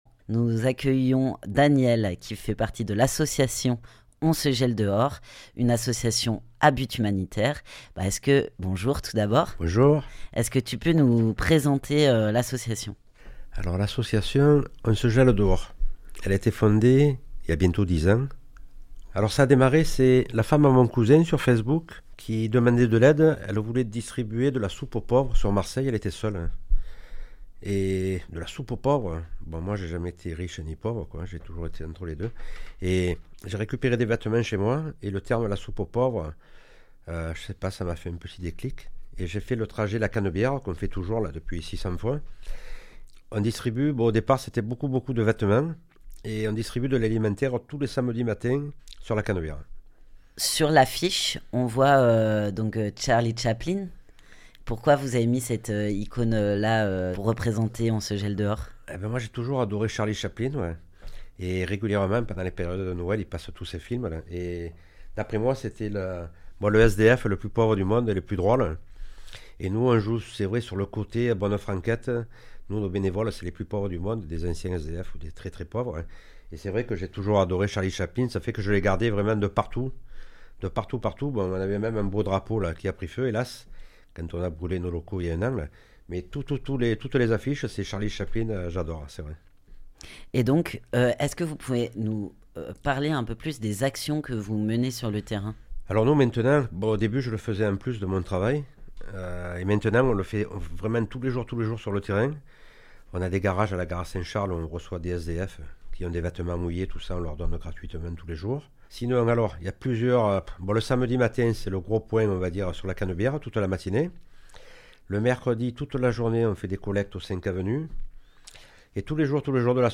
Interview
dans les locaux de Fréquence Mistral Marseille